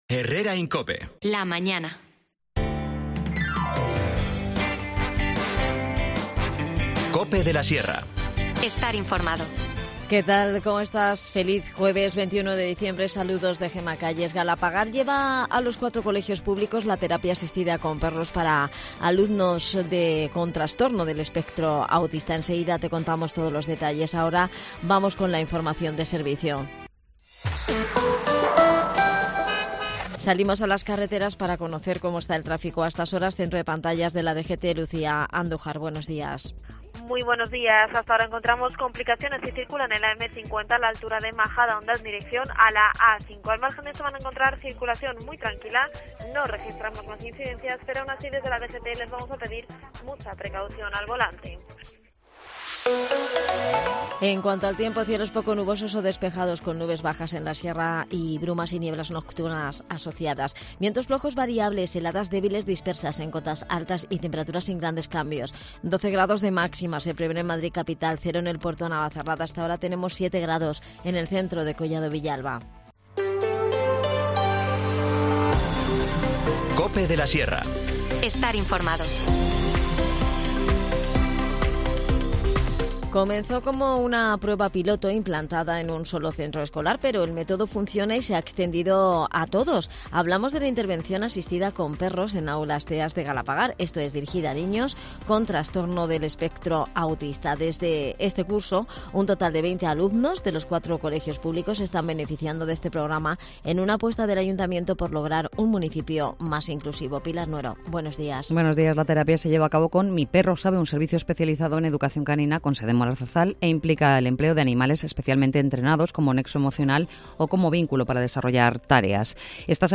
Nos lo ha explicado en el programa Adan Martínez, concejal de Comunicación, que también ha aprovechado para hablarnos de las numerosas actividades de la Programación Especial de Navidad que han organizado para niños y mayores.